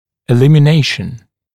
[ɪˌlɪmɪ’neɪʃn] [ə-] [иˌлими’нэйшн ], [э-] устранение, удаление, ликвидация